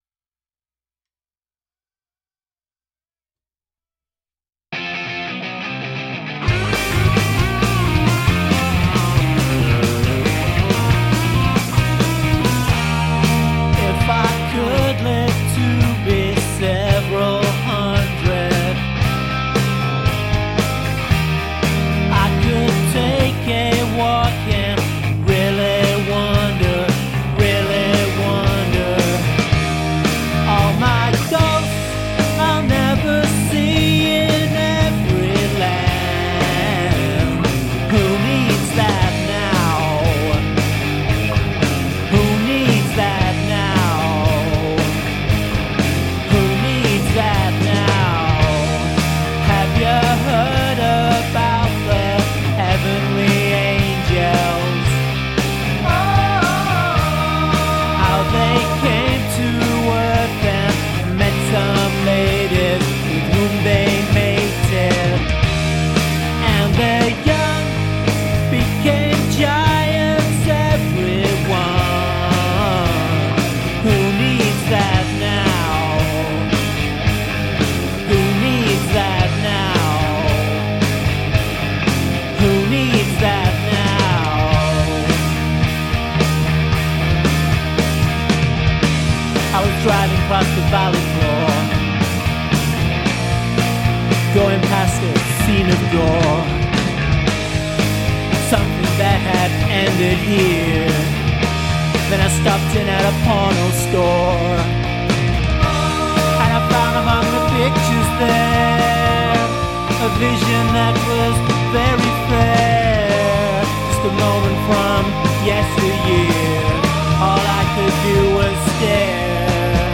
The solo 2/3 of the way through is D.I.d What do you think?